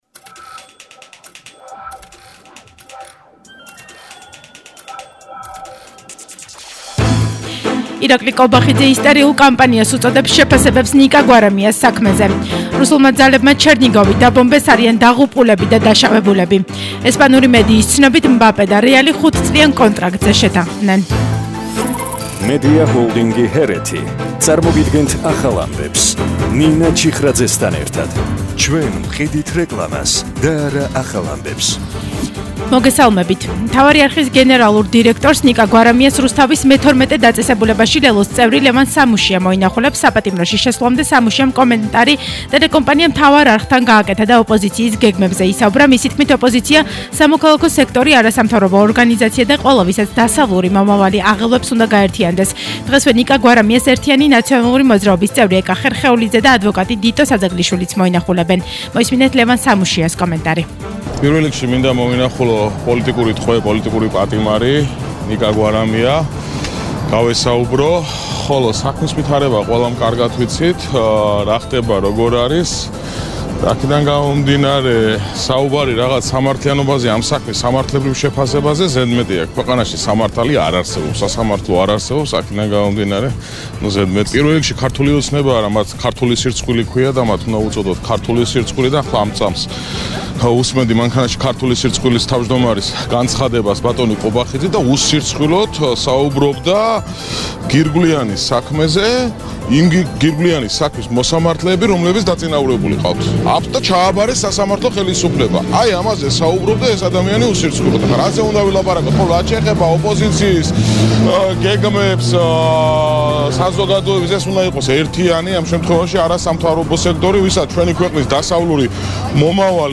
ახალი ამბები 12:00 საათზე – 17/05/22